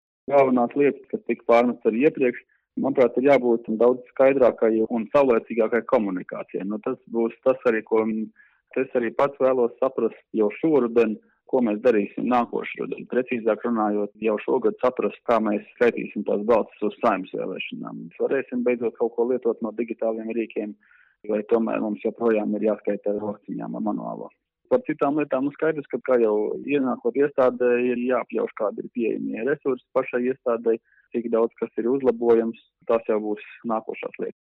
Centrālās vēlēšanu komisijas jaunais vadītājs Māris Zviedris, kurš amata pienākumus sāks pildīt 30. jūlijā: